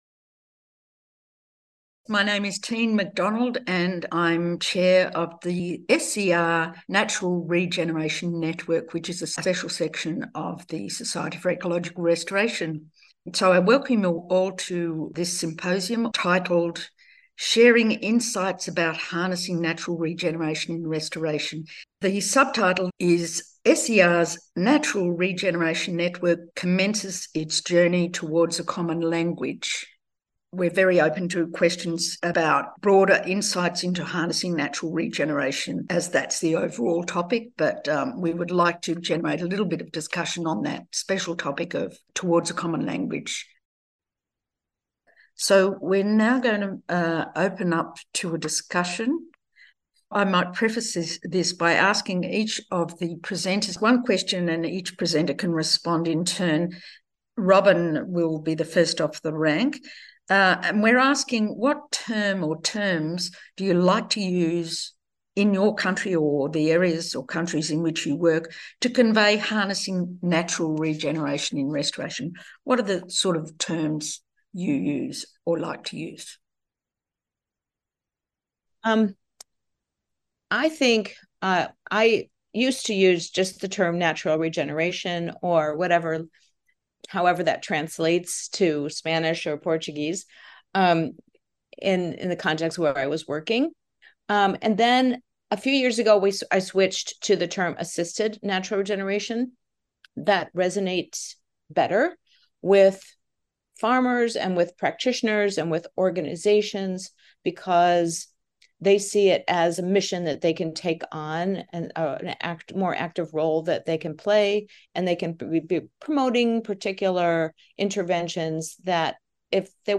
The audio recording of the very interesting discussion at the end of the talks is provided below.
SER-NRN-Discussion-QA-Recording-1.mp3